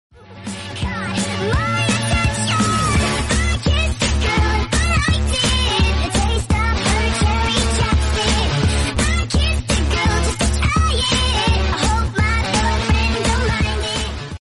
Meow Sound Effects Free Download
meow sound effects free download